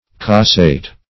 Search Result for " cassate" : The Collaborative International Dictionary of English v.0.48: Cassate \Cas"sate\, v. t. [LL. cassare.
cassate.mp3